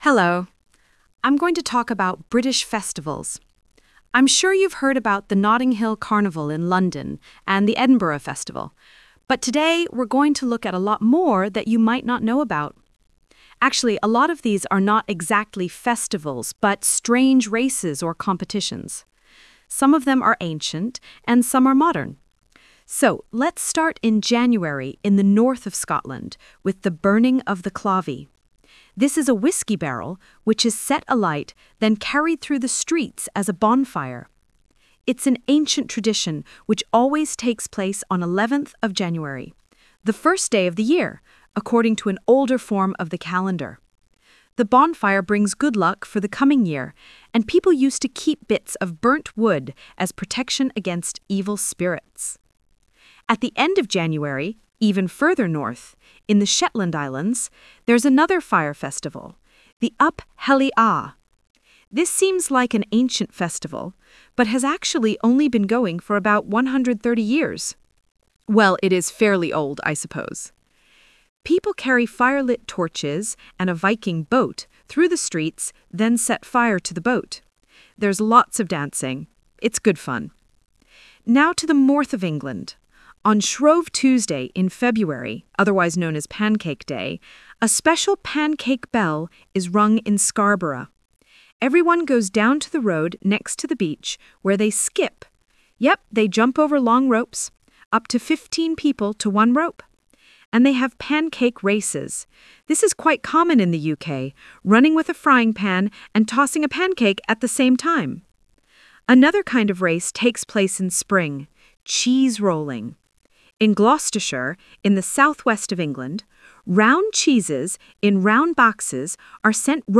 Talk/Lecture 1: You will hear a talk about British festivals.